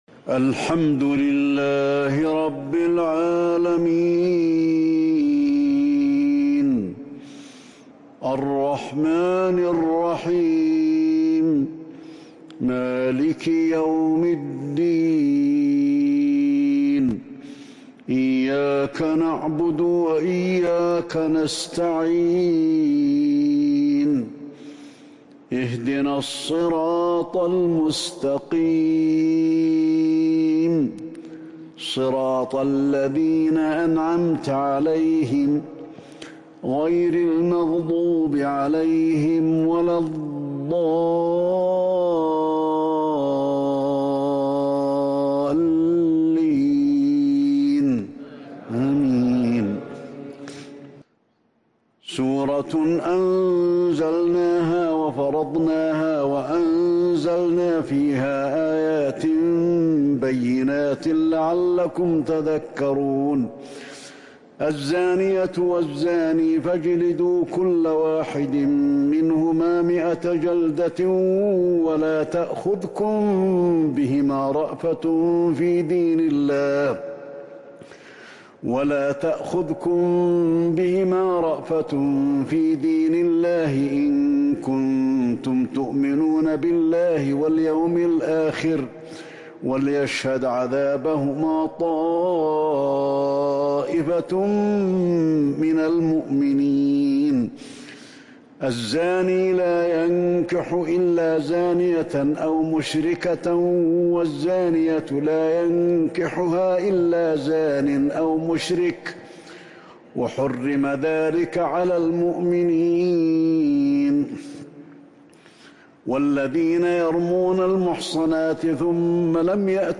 صلاة التهجّد | ليلة 22 رمضان 1442| سورة النور (1-26) | Tahajjud prayer | The night of Ramadan 22 1442 | Surah Nour > تراويح الحرم النبوي عام 1442 🕌 > التراويح - تلاوات الحرمين